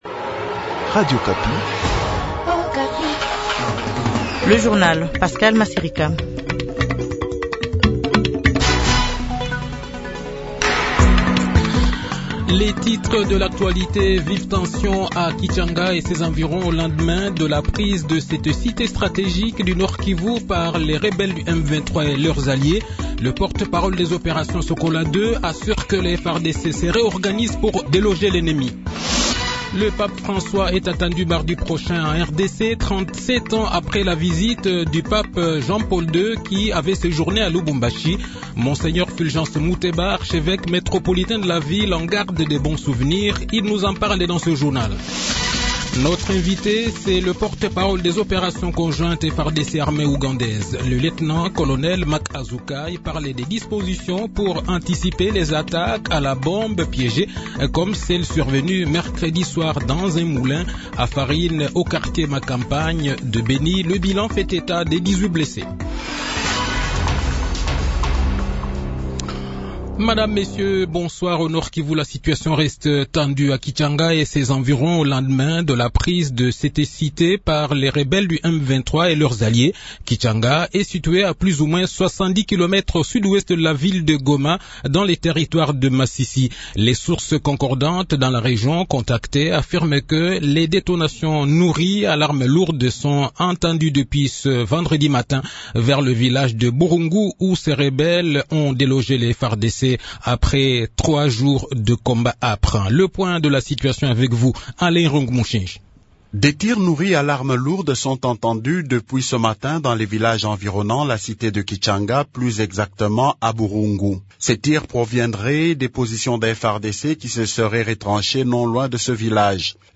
Le journal de 18 h, 27 janvier 2023